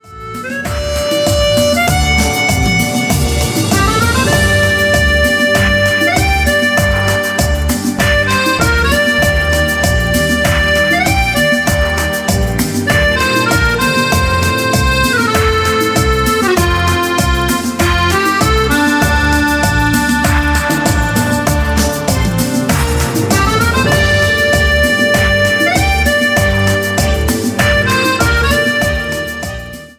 CUMBIA  (04.00)